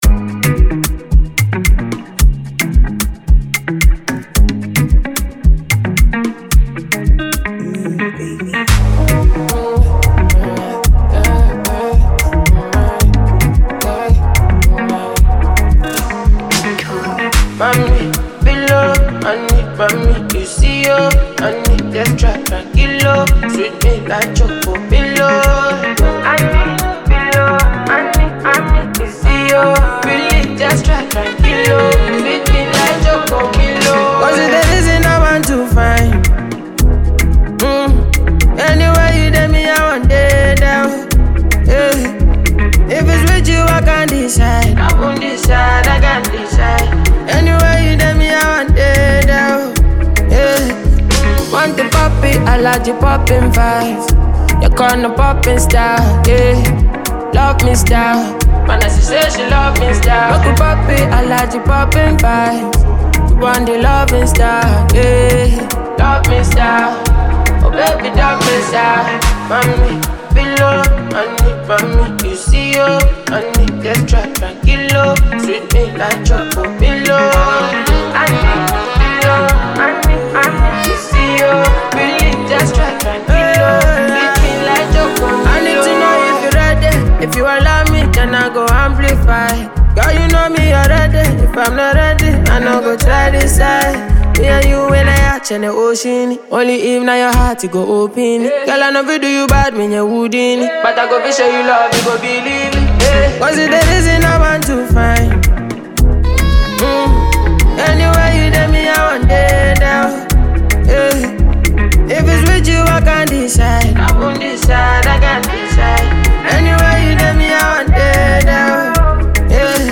well-produced tune